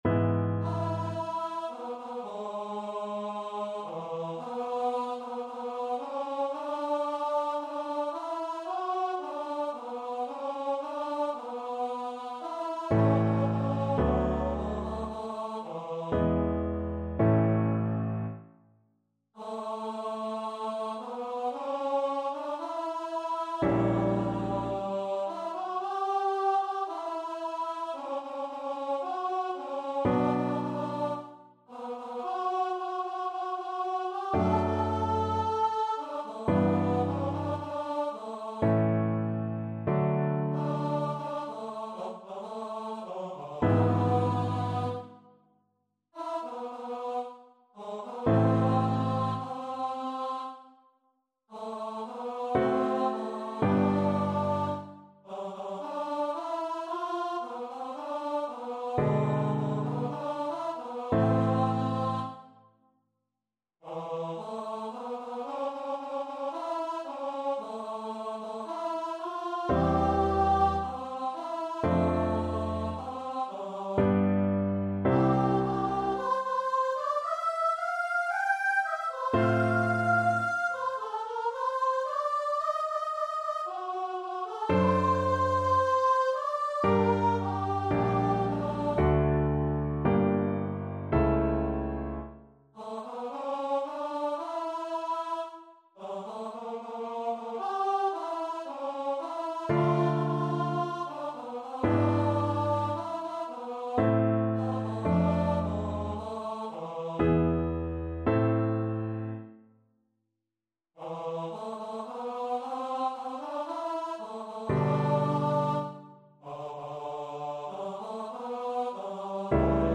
Auf das Fest; Lass ihn kreuzigen (St. Matthew Passion) Choir version
Choir  (View more Intermediate Choir Music)
Classical (View more Classical Choir Music)